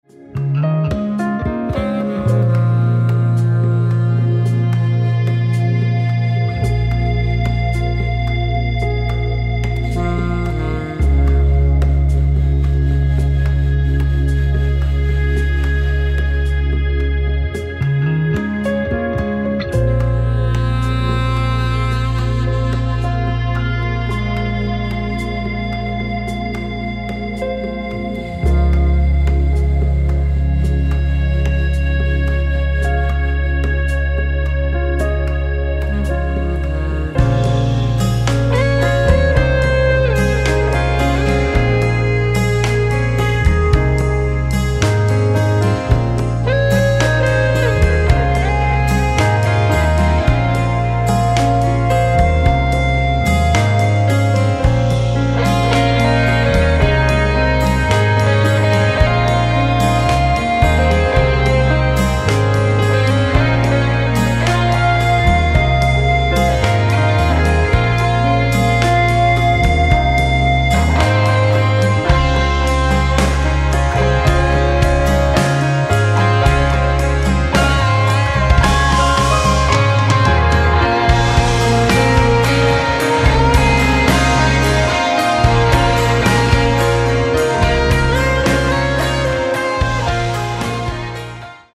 Каталог -> Рок и альтернатива -> Поэтический рок